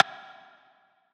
TM88 SlientPerc.wav